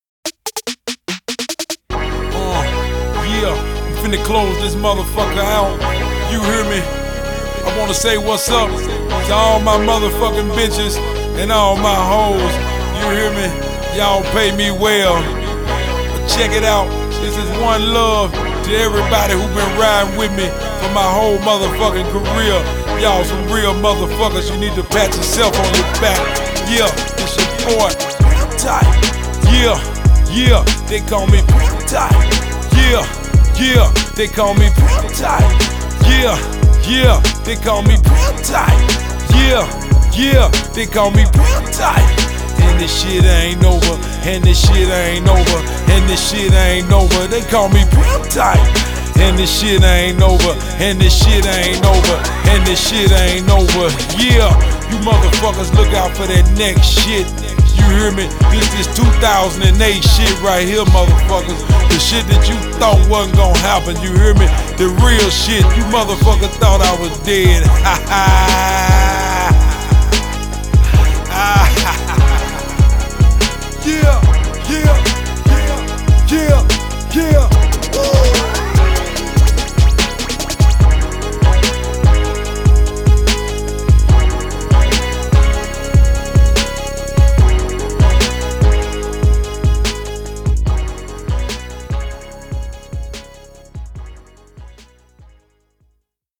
Genre: Memphis Rap.